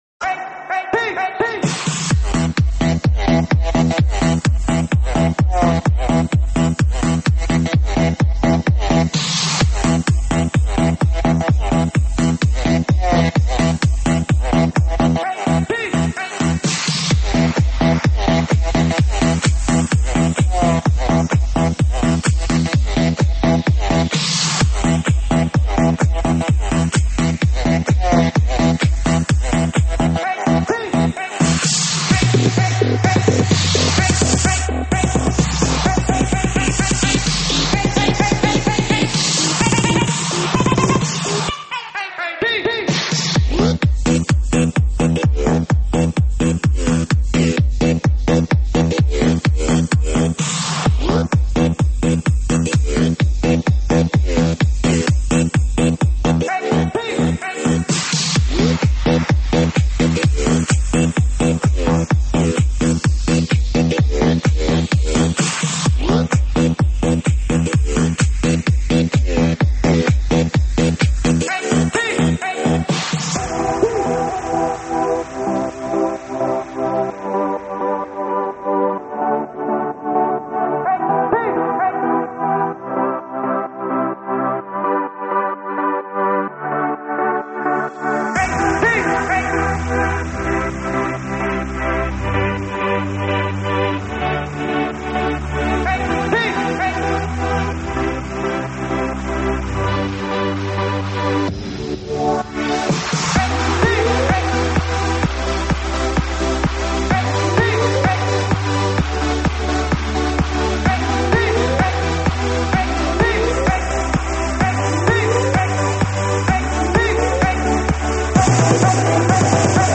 舞曲类别：3D全景环绕